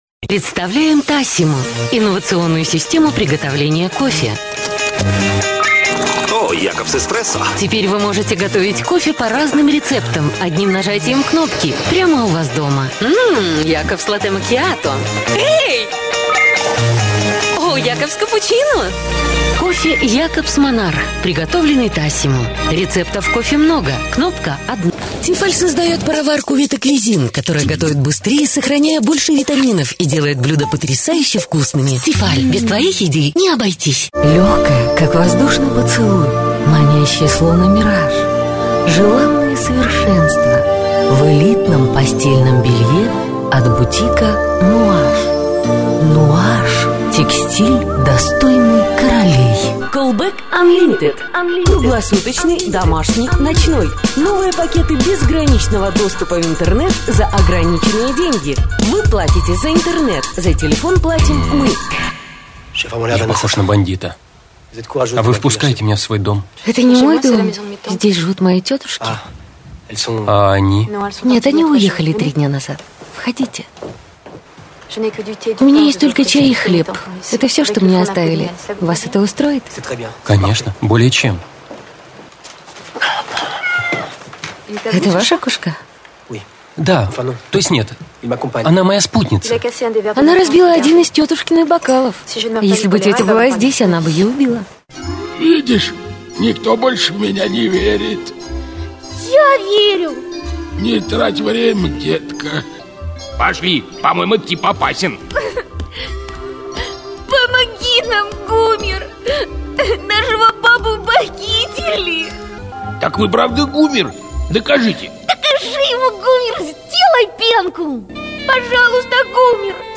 Жіноча
Профакторка, дублюю і озвучую.